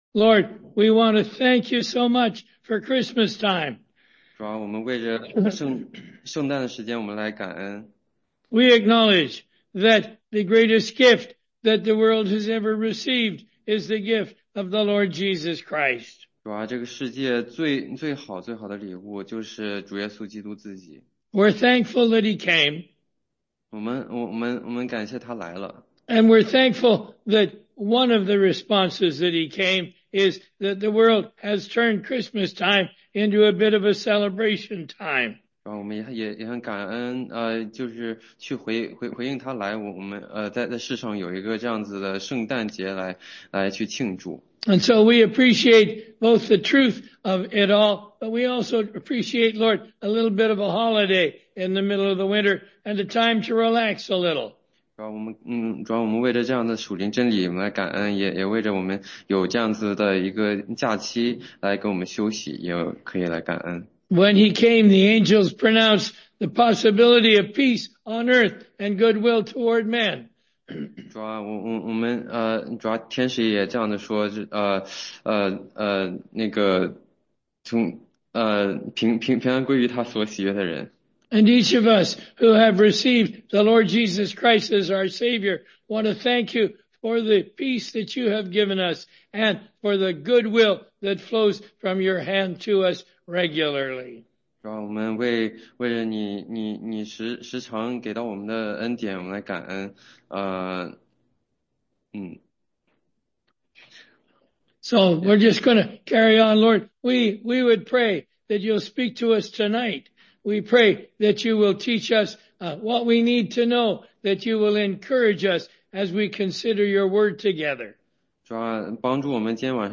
16街讲道录音 - 约翰福音解读—井边的撒马利亚妇人（4章1-30节）
中英文查经